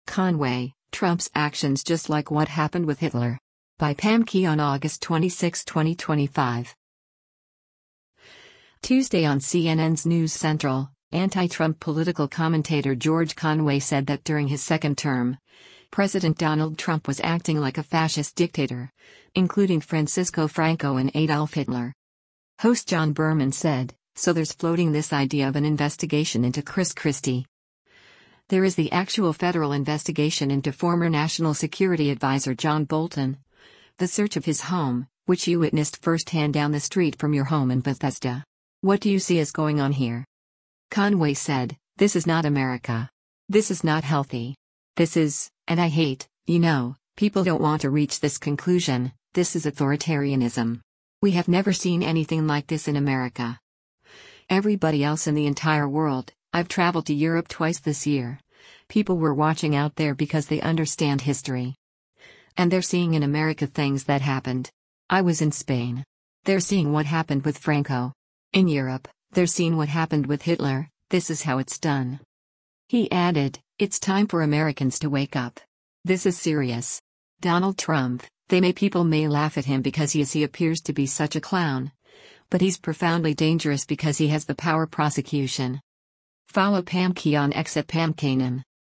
Tuesday on CNN’s “News Central,” anti-Trump political commentator George Conway said that during his second term, President Donald Trump was acting like a fascist dictator, including Francisco Franco and Adolf Hitler.